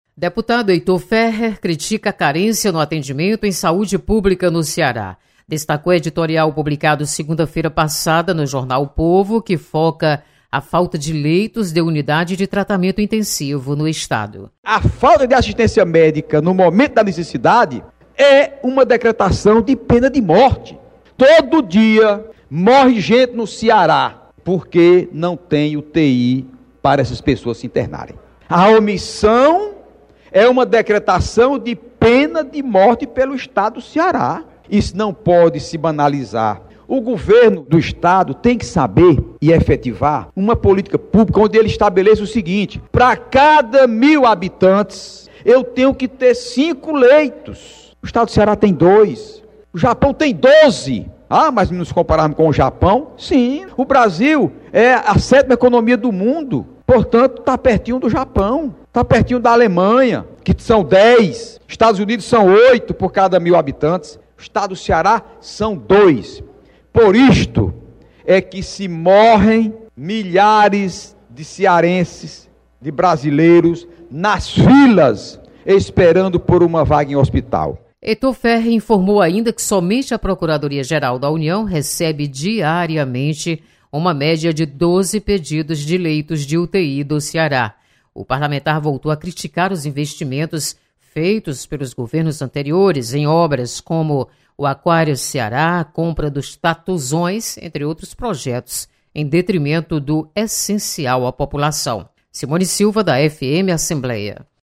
Falta de leitos de UTI preocupa deputado Heitor Férrer. Repórter